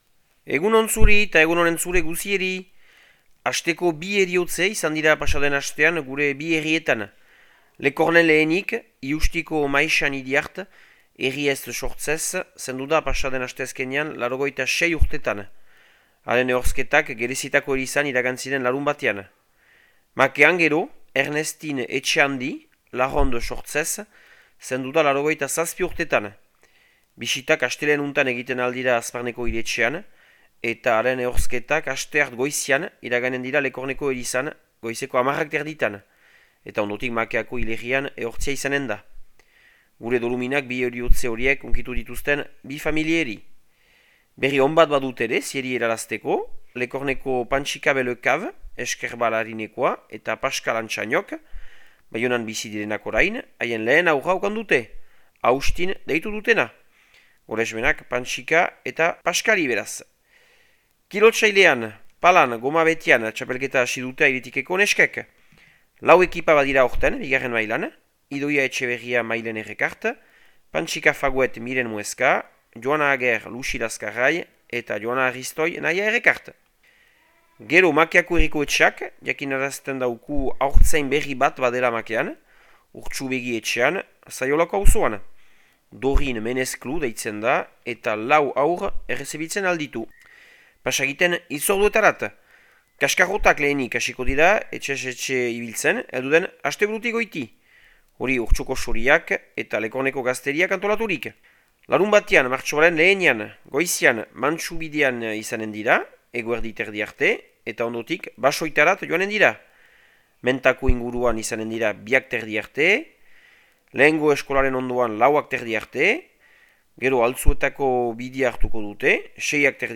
Otsailaren 24ko Makea eta Lekorneko berriak